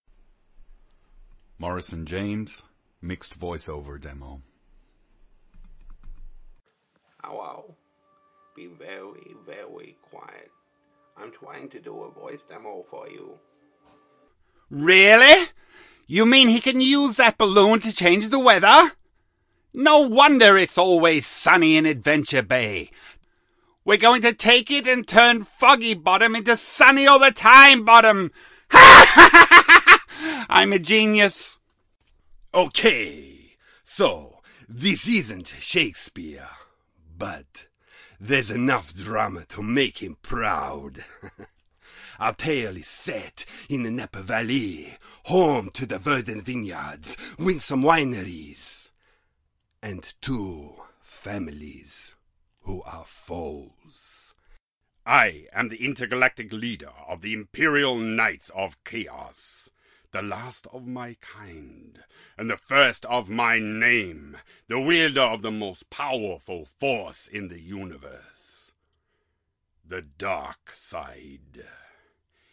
Voice Demo Reel